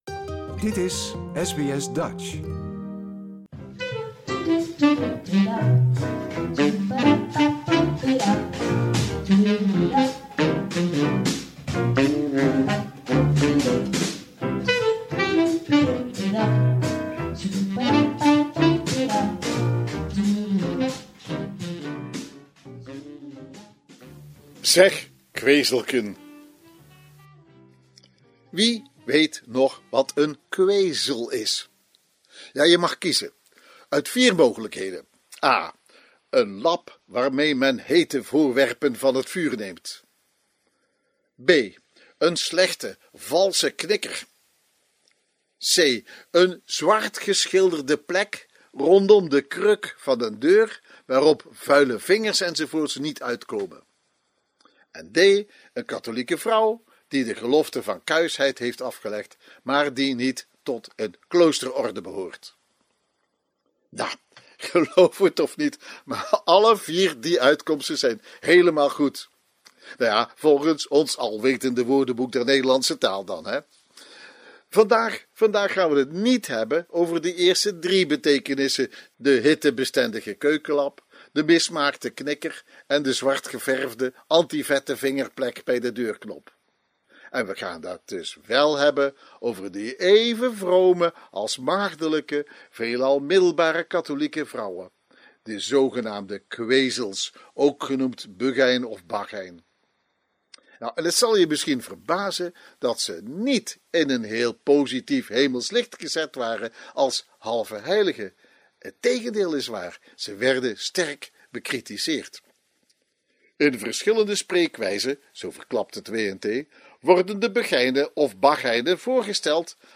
legt het uit in de vorm van een gezongen liedje, haarfijn en luidkeels.